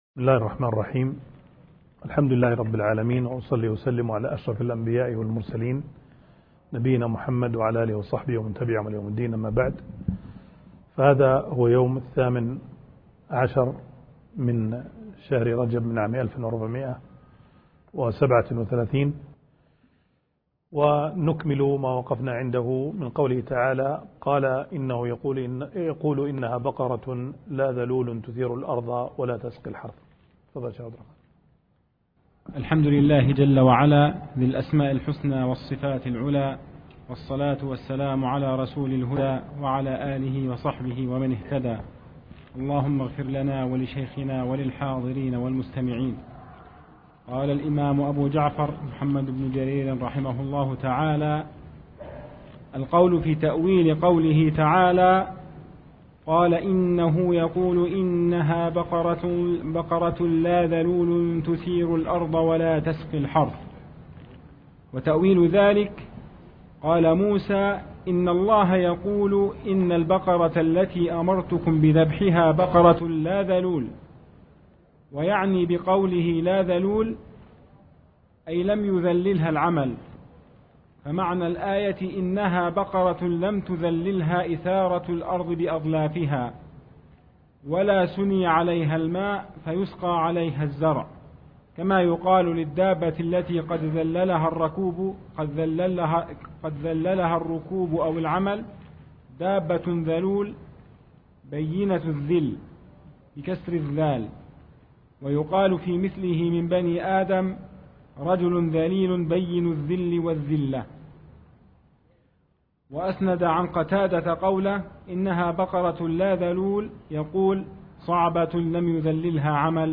التعليق على تفسير الطبري الدرس -68- - سورة البقرة الآيات -71-73